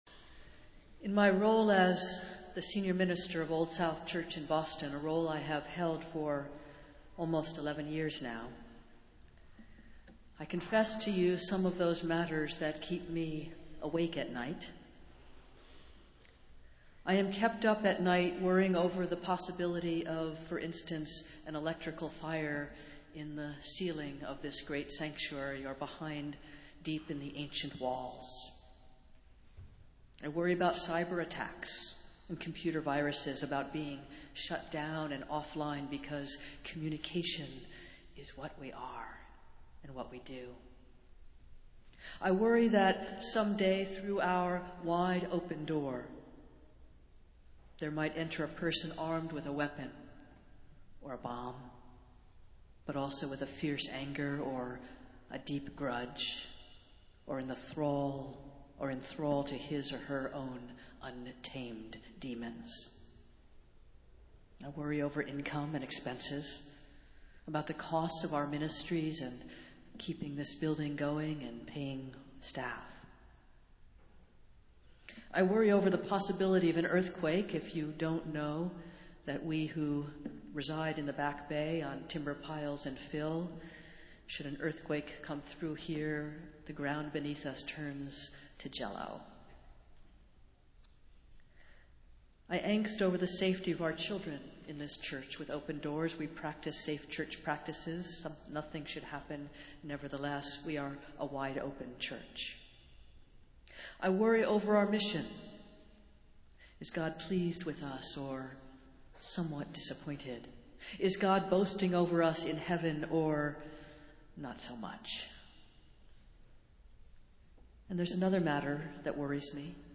Festival Worship - Hymn Festival Sunday